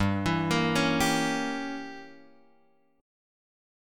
G Diminished 7th